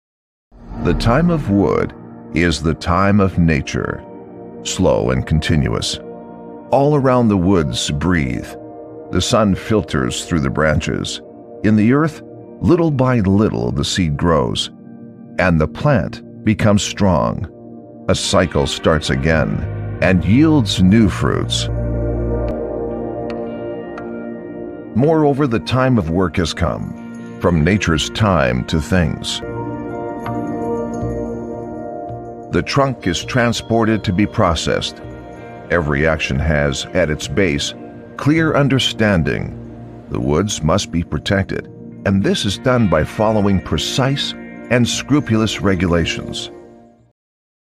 Englisch (Kanadisch)
Audioguides
Tief und bestimmend mit eleganter Klarheit und Wärme.
Apex 460 & Sennheiser E-Serie